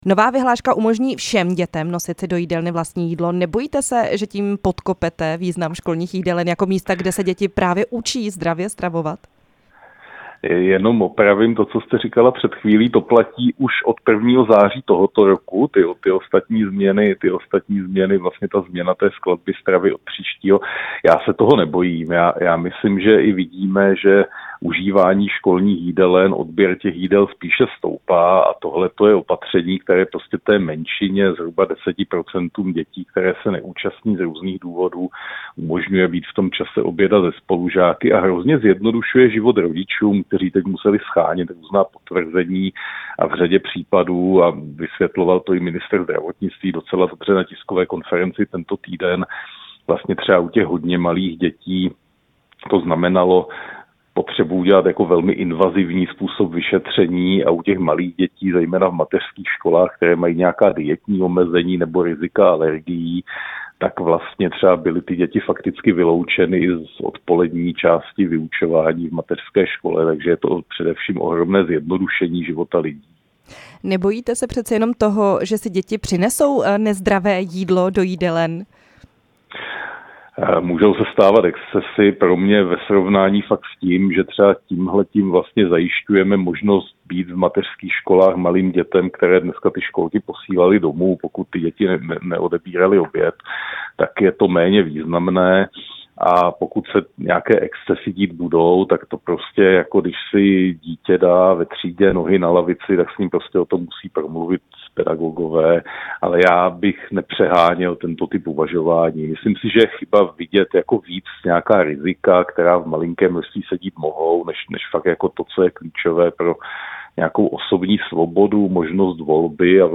Rozhovor s náměstkem ministra školství Jiřím Nantlem